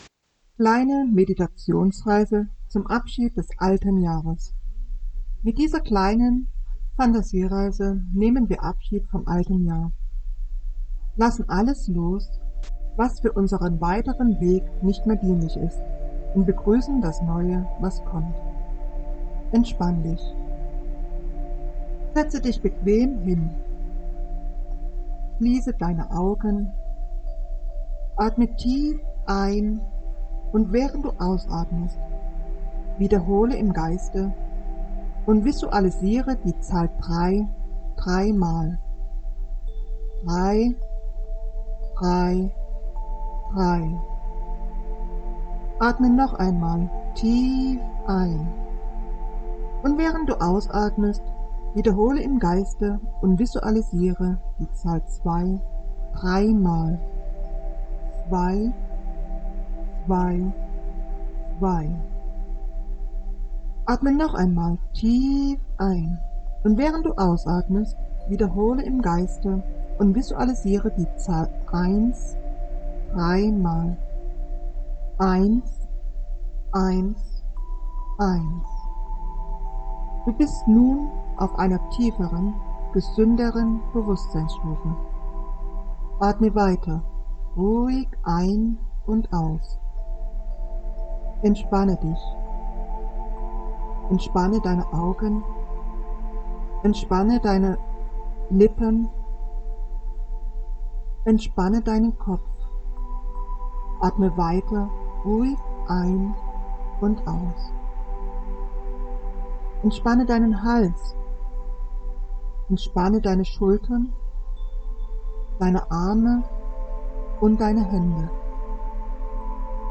Die heutige Meditation ist eine Phantasiereise an unseren Stausee.